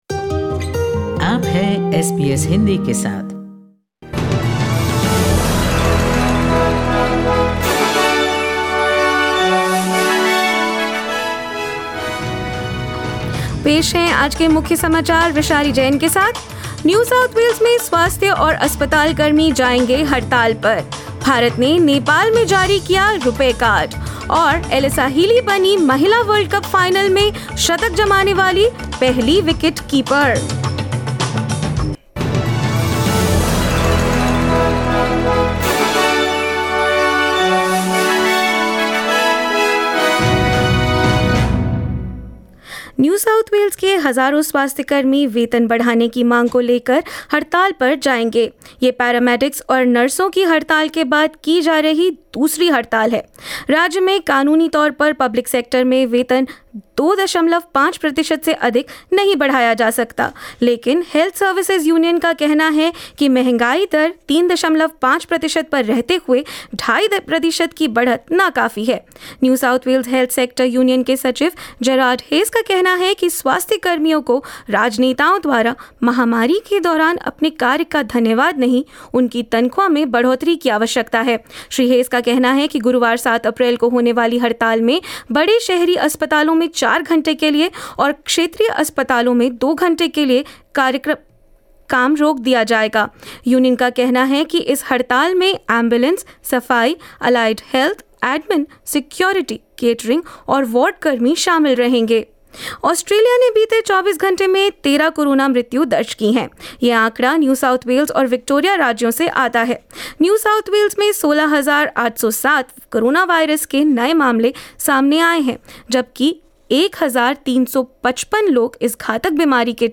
In this latest Hindi bulletin: Thousands of health and hospitals workers in NSW will take industrial action on Thursday to demand a pay hike; Nepal becomes the fourth country to operationalise the Indian payment system, RuPay Card; Alyssa Healy becomes first wicket-keeper-batter to score a century in Women's Cricket World Cup final and more news.